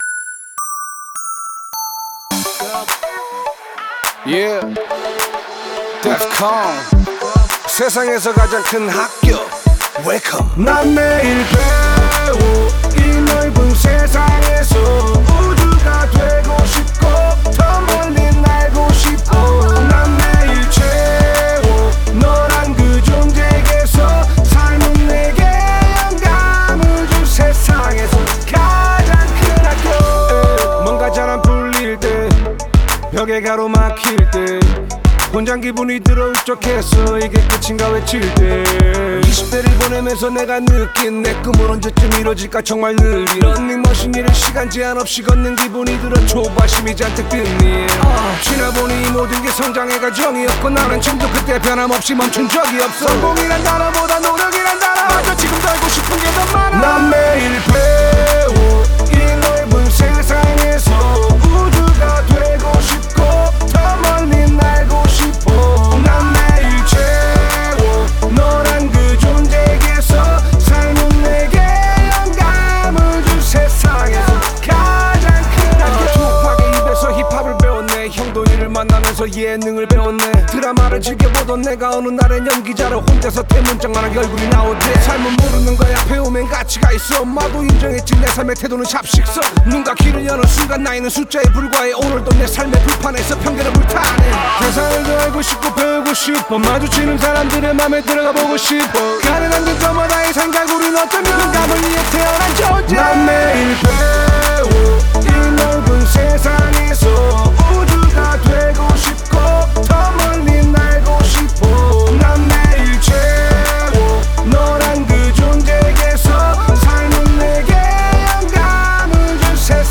힙합 버스킹